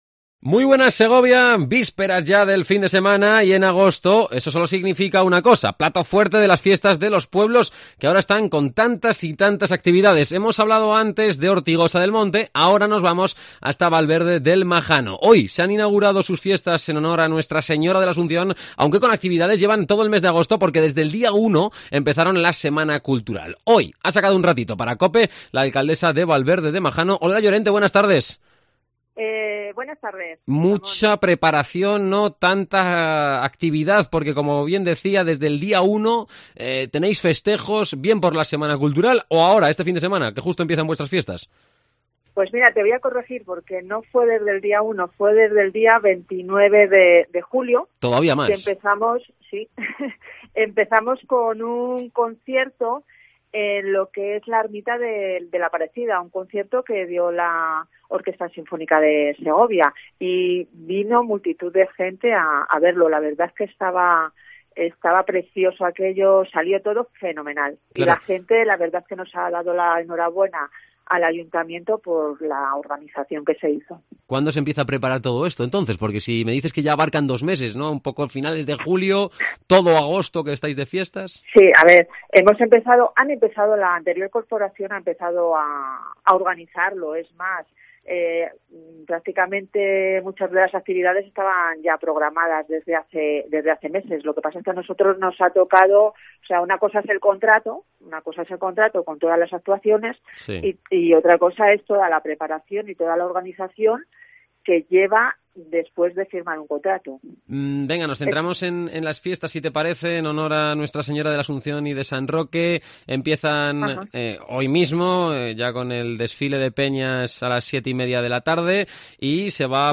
Entrevista a Olga Llorente, Alcaldesa de Valverde del Majano